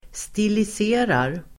Ladda ner uttalet
Uttal: [²stilis'e:rar]